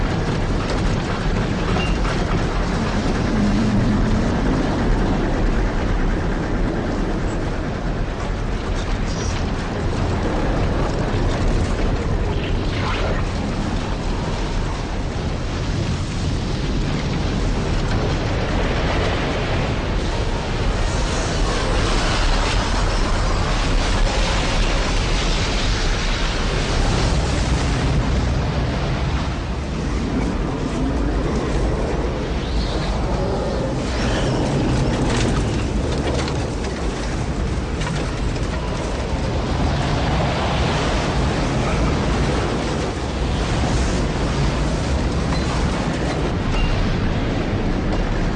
tornado.ogg